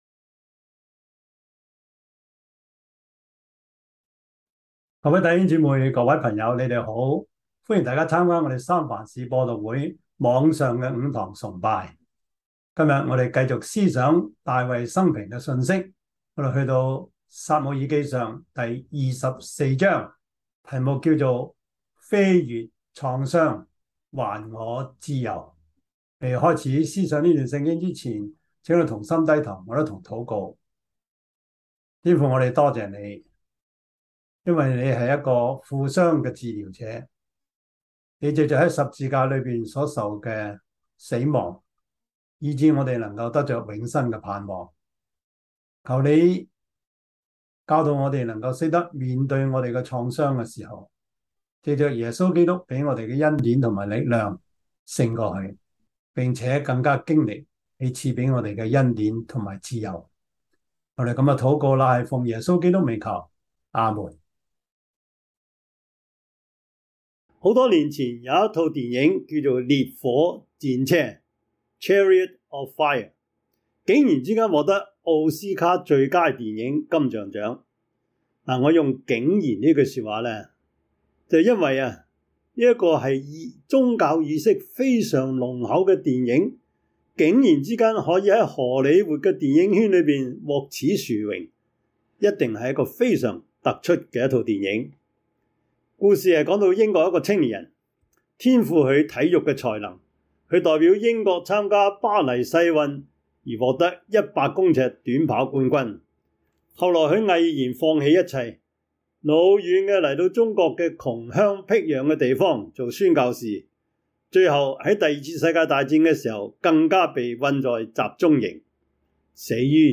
撒母耳記上 24:1-22 Service Type: 主日崇拜 撒 母 耳 記 上 24:1-22 Chinese Union Version
Topics: 主日證道 « 信靠和拆毁 把別人介紹給主 – 第十六課 »